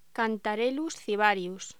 Locución: Cantharellus cibarius